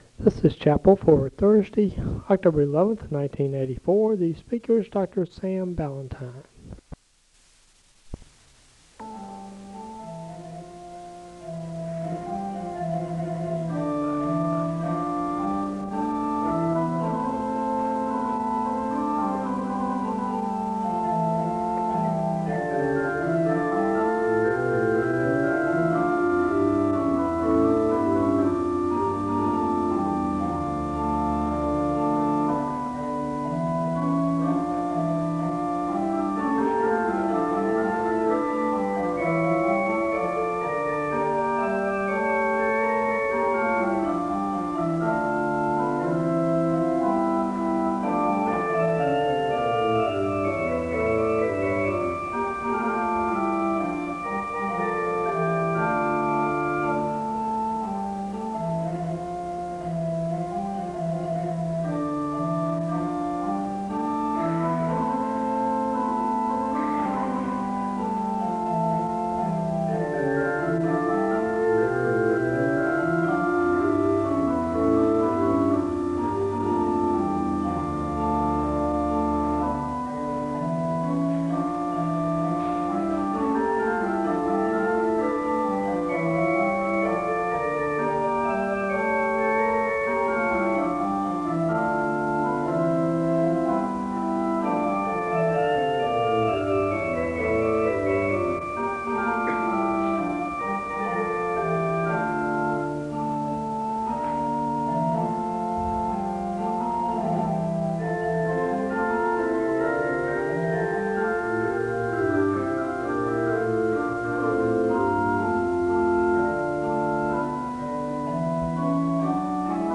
The service begins with organ music (00:00-04:00).
The speaker delivers the Scripture reading, and he gives a word of prayer (04:01-05:45). The speaker reads from Mark 9:10-29 (05:46-08:10).
SEBTS Chapel and Special Event Recordings